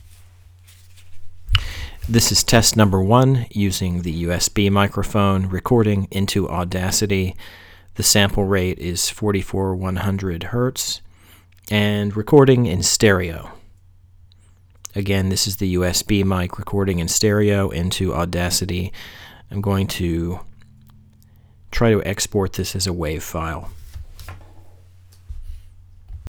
Audio Technica AT2020USB Cardioid Studio Condenser Microphone, samples recorded into Audacity, using a pop-filter (only one mic, even with Stereo recording).
Recorded in Stereo, 16-bit WAV file.
sample1USBmic.wav